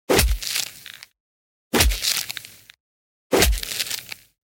دانلود آهنگ دعوا 22 از افکت صوتی انسان و موجودات زنده
دانلود صدای دعوا 22 از ساعد نیوز با لینک مستقیم و کیفیت بالا
جلوه های صوتی